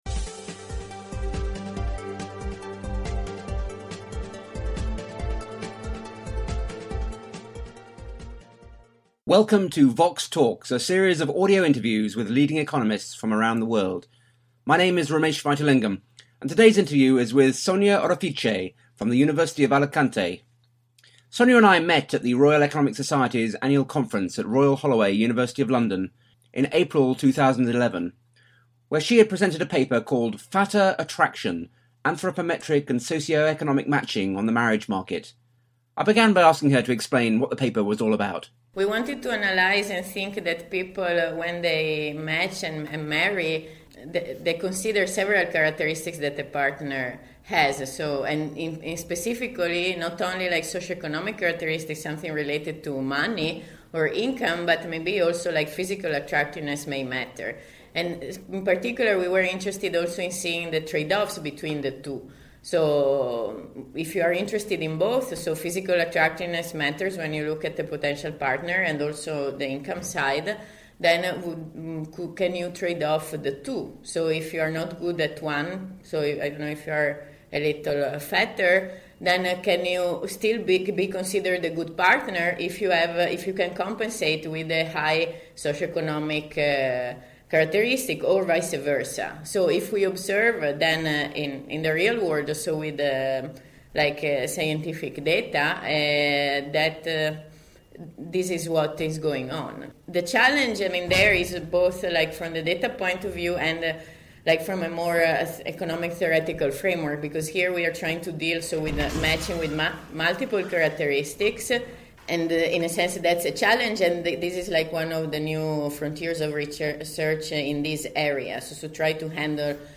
The interview was recorded at the Royal Economic Society’s annual conference at Royal Holloway, University of London, in April 2011